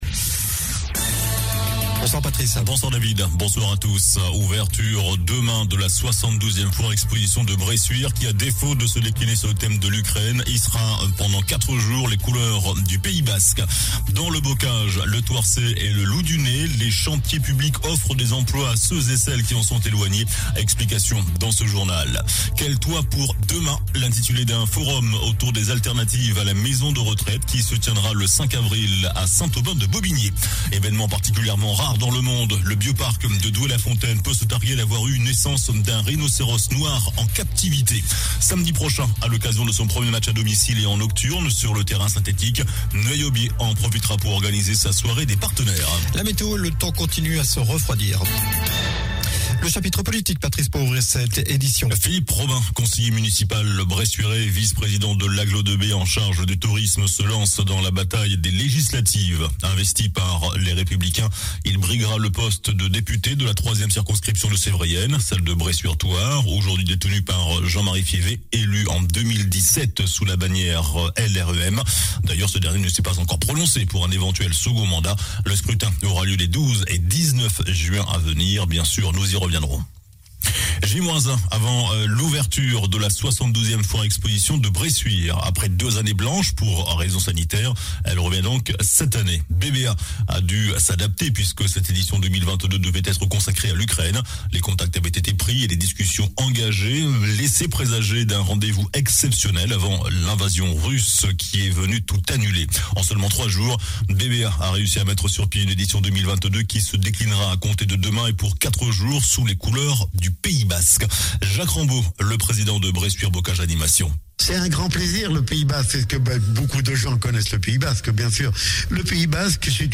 JOURNAL DU JEUDI 31 MARS ( SOIR )